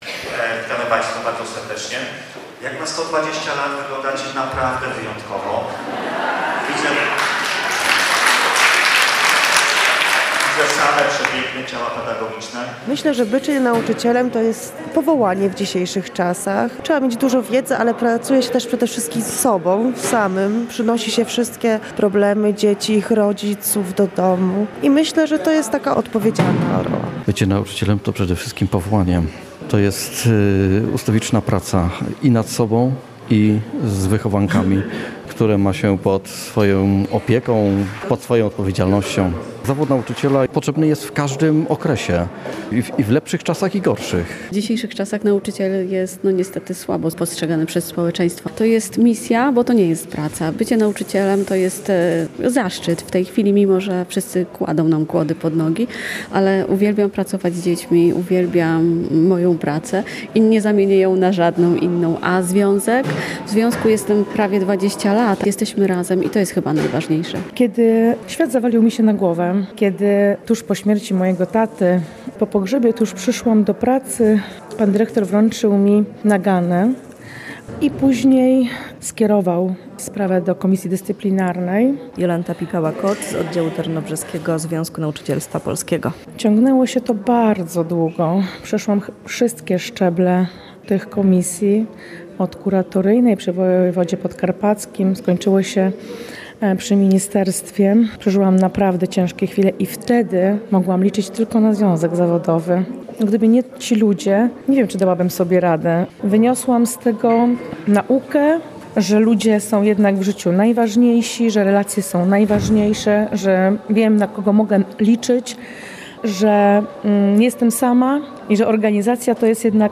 Wiadomości • 25 października w auli Zespołu Szkół Technicznych w Mielcu odbyły się uroczyste obchody 120-lecia Związku Nauczycielstwa Polskiego.